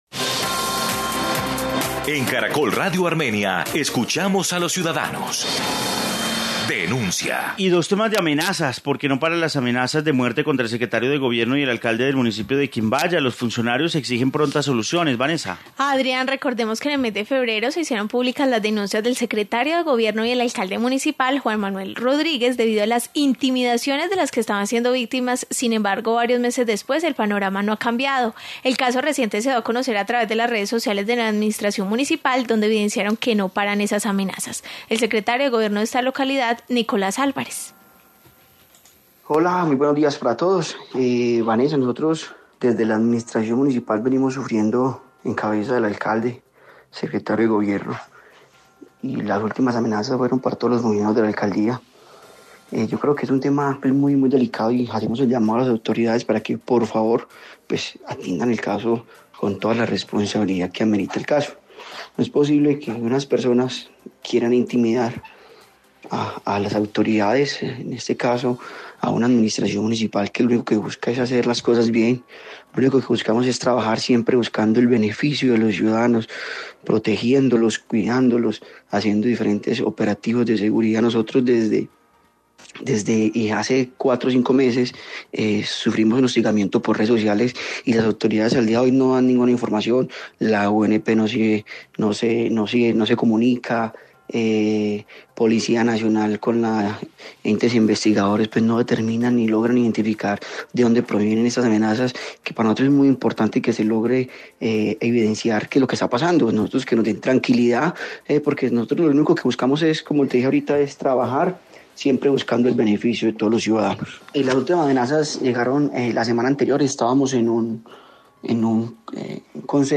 Informe sobre amenazas